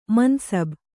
♪ man sab